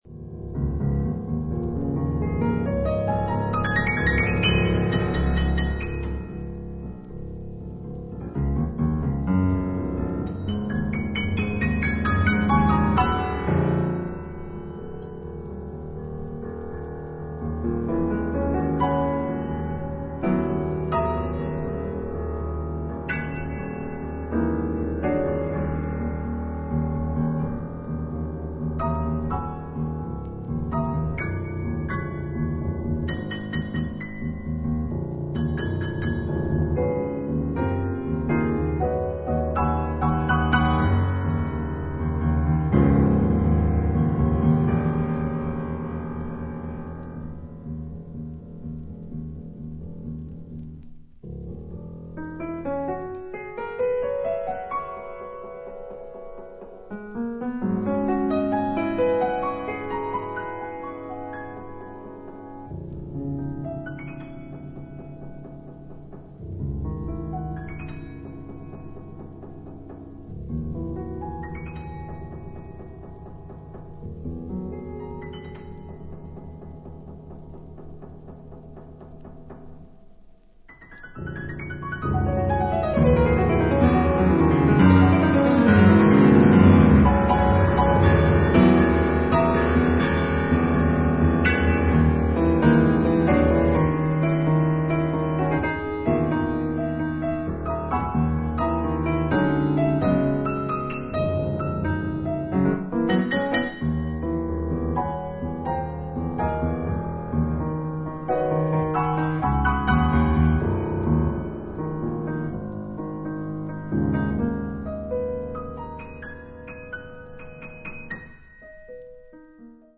内省的でありながら、フェロー独特の穏やかな響きが感じられるピアノ独奏曲が計4曲収録。